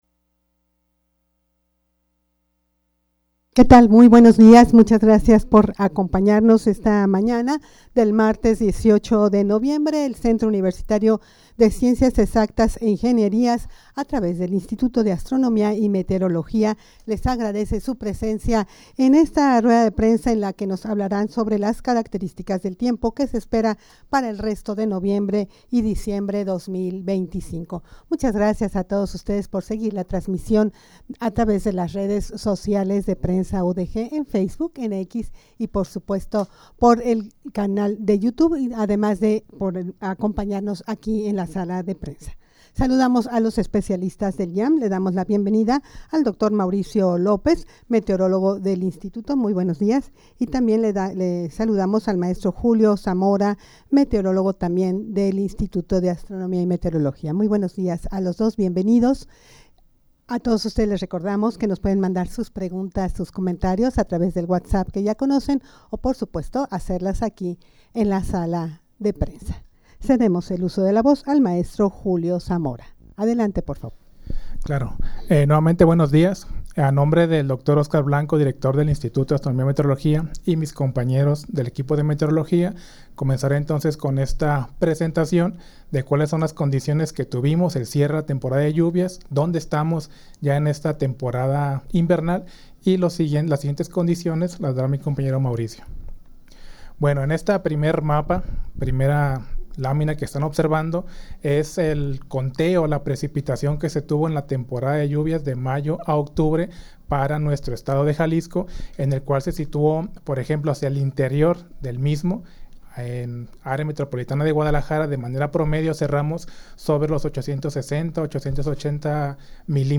rueda-de-prensa-caracteristicas-del-tiempo-que-se-esperan-para-el-resto-de-noviembre-y-diciembre-2025.mp3